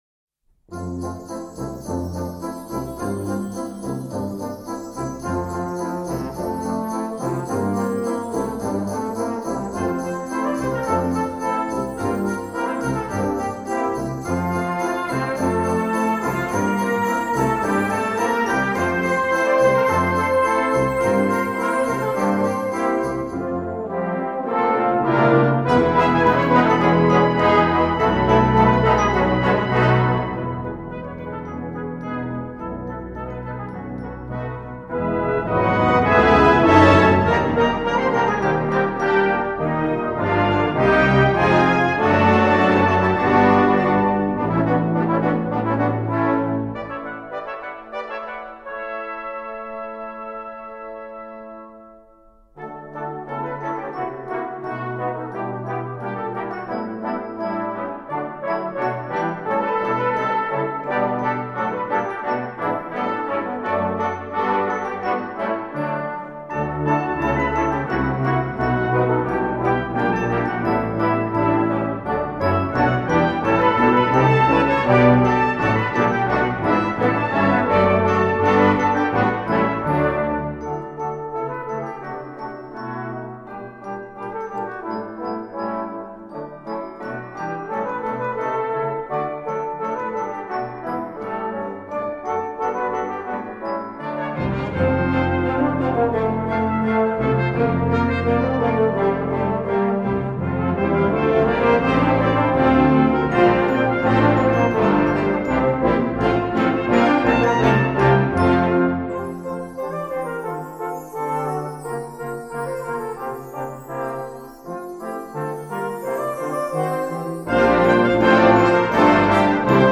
Besetzung: Children Choir & Brass Band